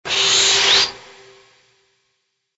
SA_shred.ogg